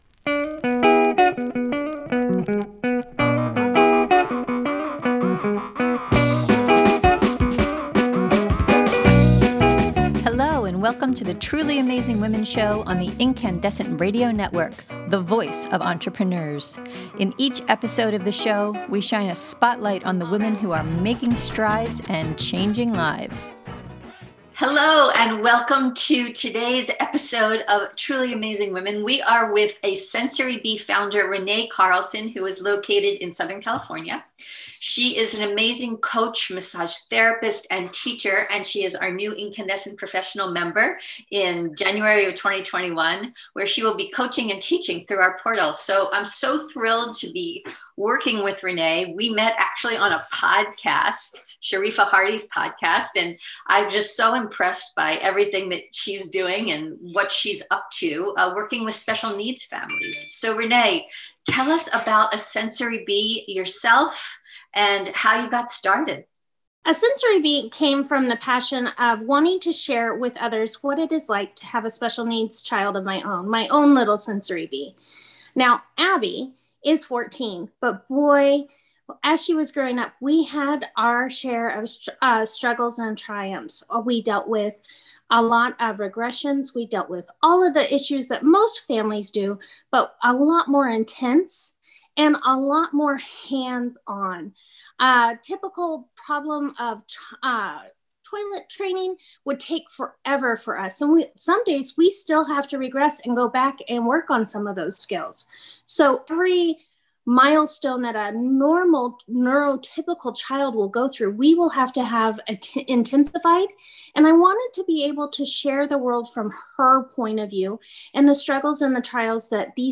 Listen now to our podcast interview with this Truly Amazing Woman!